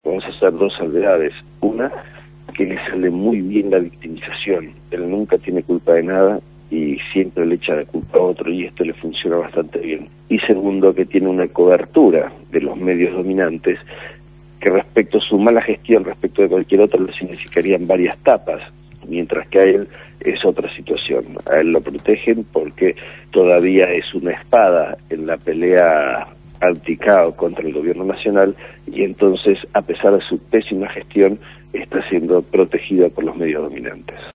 Lo dijo Aníbal Ibarra, Diputado de la Ciudad por el partido Diálogo por Buenos Aires, fue entrevistado en el programa «Punto de Partida» de Radio Gráfica